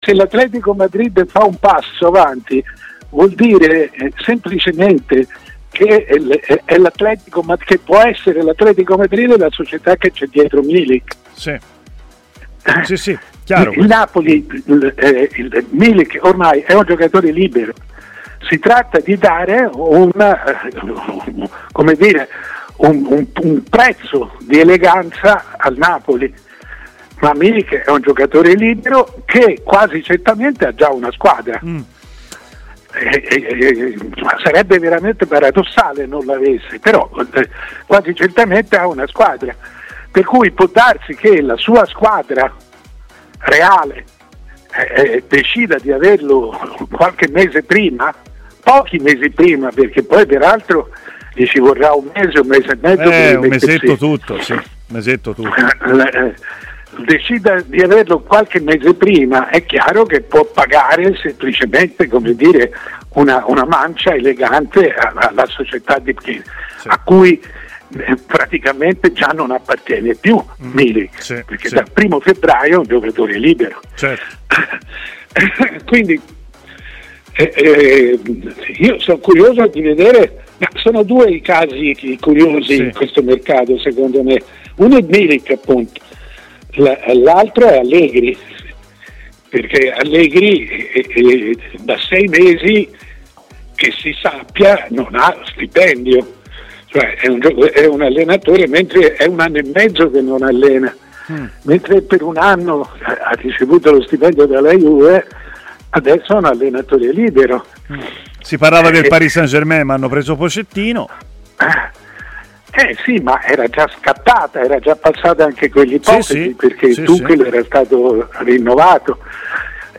Il direttore Mario Sconcerti ha ai microfoni di TMW Radio partendo dal possibile arrivo di Milik all'Atletico Madrid.